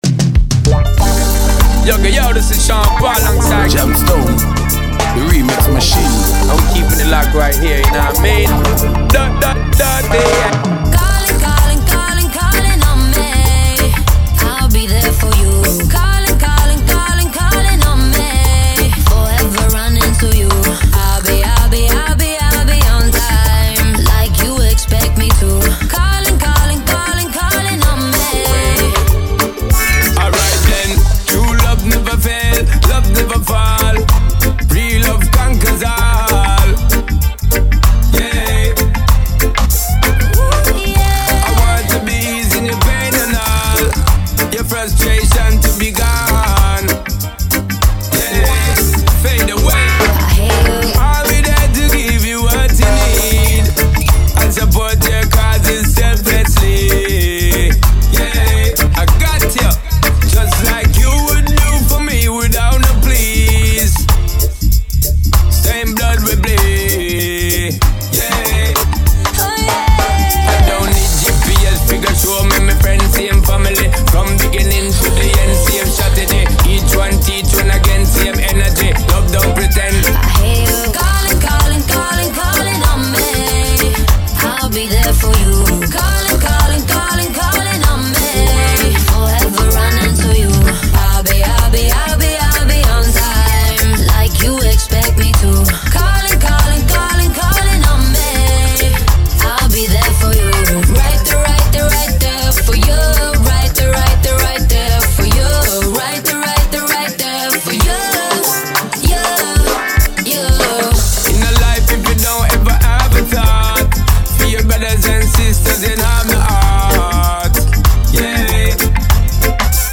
Vocal track
riddim